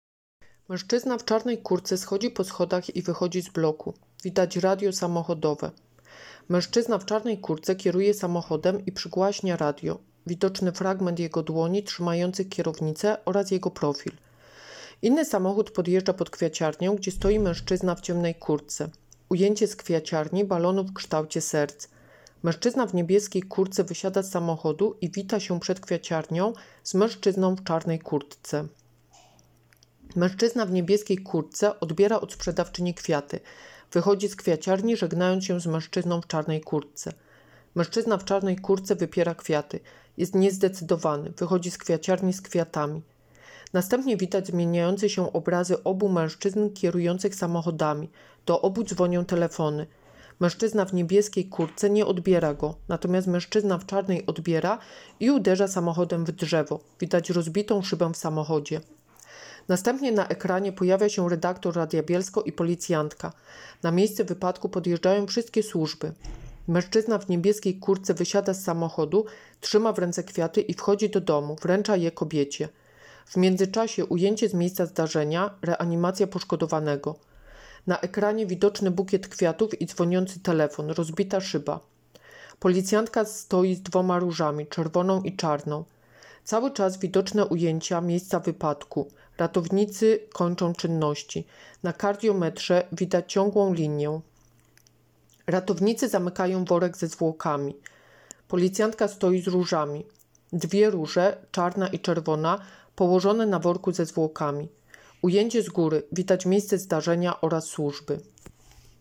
Nagranie audio Audiodeskrypcja spotu